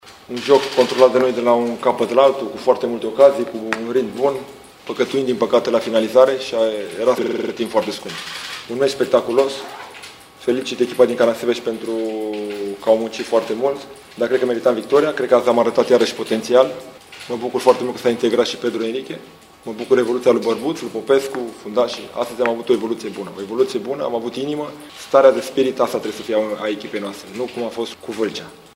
Antrenorii celor două echipe au vorbit după meci